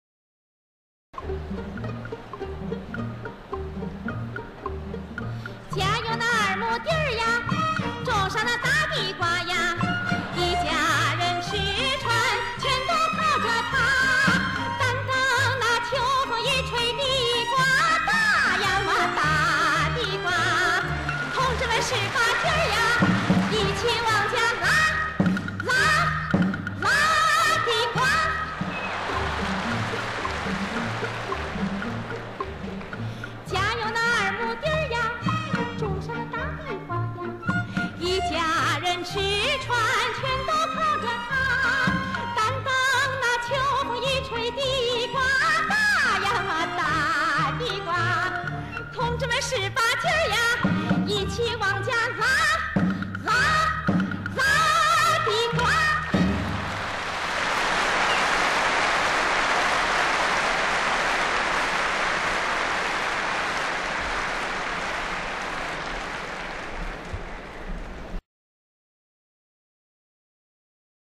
[31/5/2018]山东民歌《拉地瓜》朱逢博1980年代济南个唱现场录音
1980年代初朱逢博曾到济南举办个人音乐会。她额外为家乡的观众演唱了一首山东小曲儿《拉地瓜》。
这款录音由山东人民广播电台采录，虽然收音效果一般，曲子也短，但不难体会当年台上台下的热切互动。
听上去，朱老师抓住了那几个关键的山东方言音，效果很好。